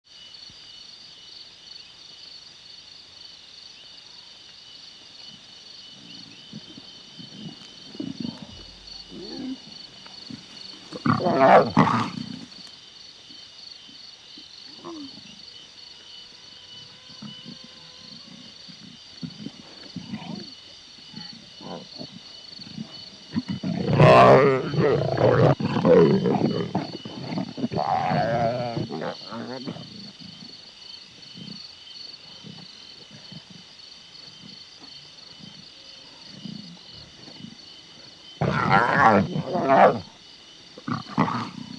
Name Last modified Size Description Parent Directory - LION AMBI00L.wav 2021-09-23 22:03 3.5M LION AMBI00R.wav 2021-09-23 22:03 3.5M Lion Ambience.s3p 2021-09-23 22:03 805 Lion Ambience Xp.s3p 2021-09-23 22:03 805
LION AMBI00L.wav